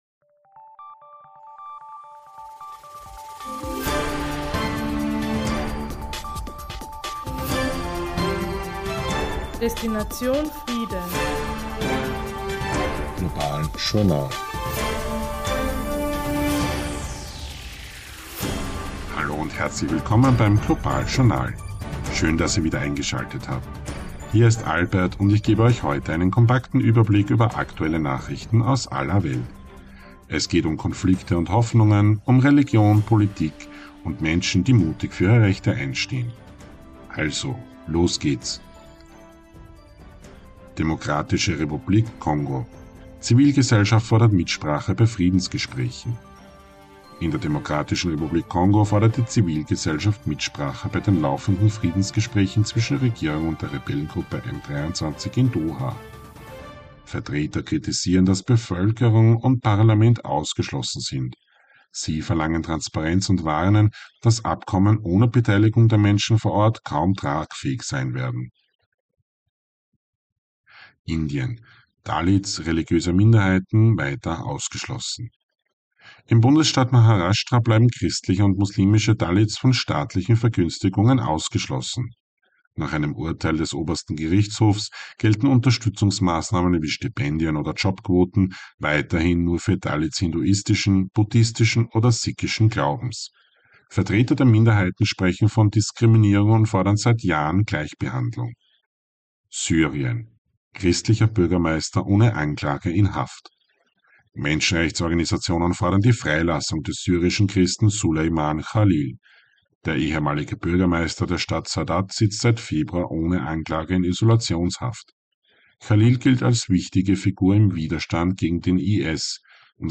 News Update Oktober 2025